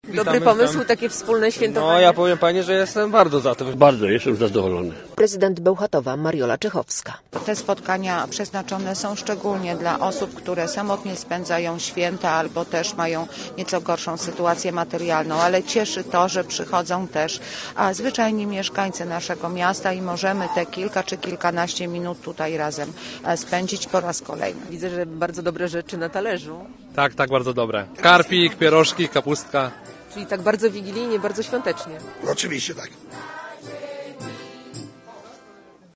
Bełchatowianie łamali się opłatkiem na wspólnej wigilii. Przedświąteczne spotkanie odbyło się na pl. Narutowicza.